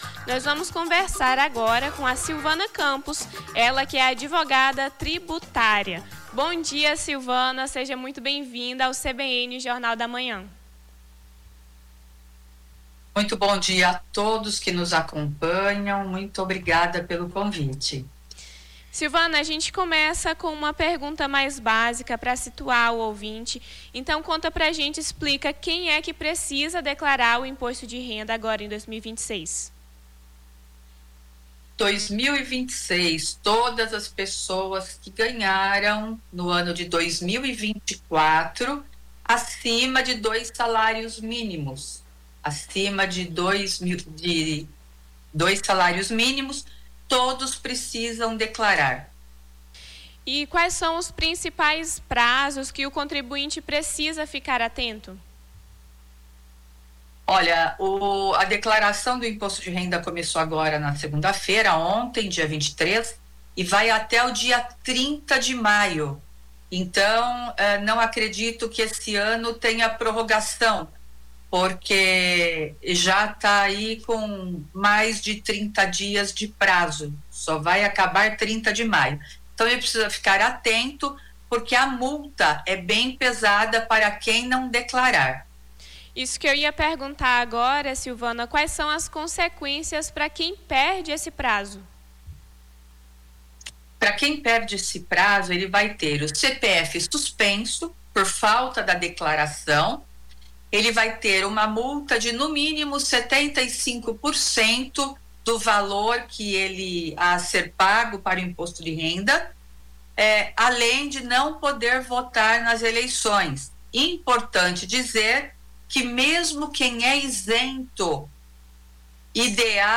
ENTREVISTA IMPOSTO DE RENDA